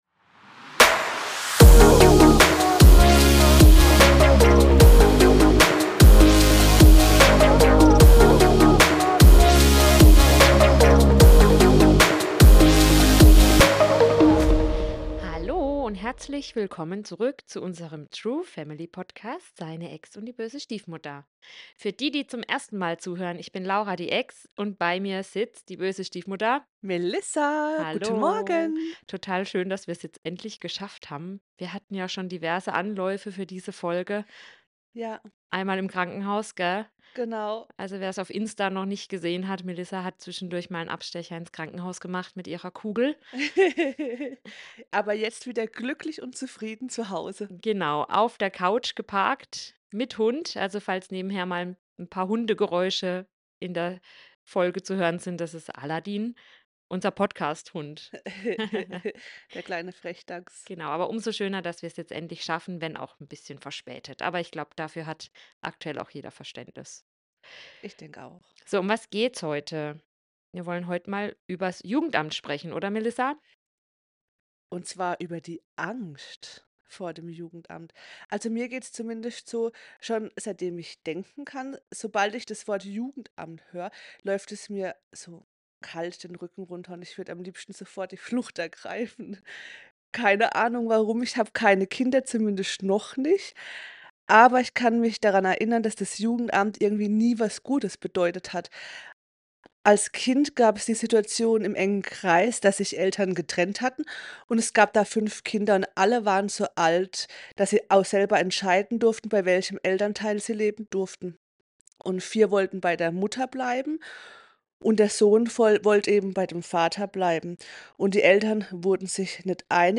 Außerdem sprechen sie darüber, welche Unterstützung es neben dem Jugendamt gibt – ganz ohne Druck und Panik. Eine ehrliche, warme Folge, die Sorgen nimmt und Klarheit schafft.